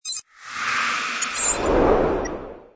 ui_nav_map_fade.wav